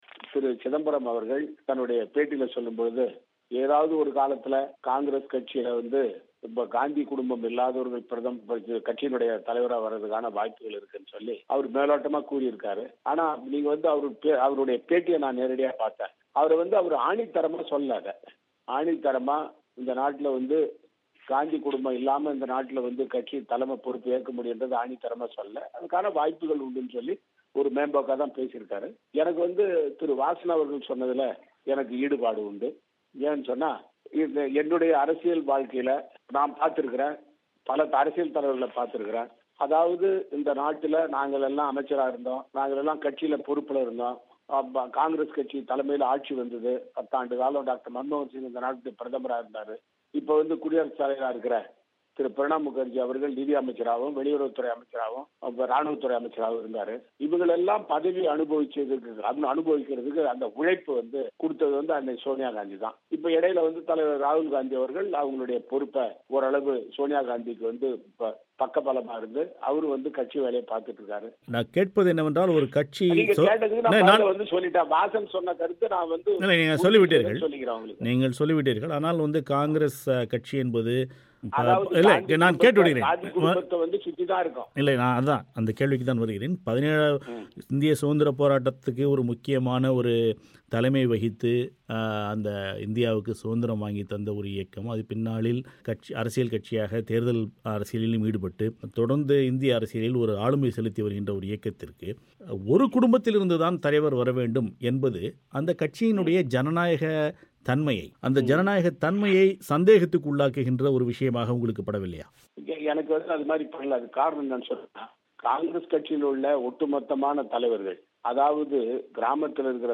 இந்தக் கேள்வியை, சமீபத்தில் காங்கிரஸ் கட்சியின் பொதுச்செயலாளர்களில் ஒருவராக நியமிக்கப்பட்டிருக்கும் முன்னாள் அமைச்சர் வி.நாராயணசாமியிடம் பிபிசி தமிழோசை கேட்டபோது, காங்கிரஸின் வெற்றிக்கும் அடிப்படையில் சோனியா காந்தியின் உழைப்பே காரணம், தொண்டர்களும் சோனியா காந்தியின் தலைமையையே விரும்புகிறார்கள் என்றார்.